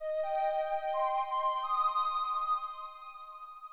INT String Riff Eb-C-Ab.wav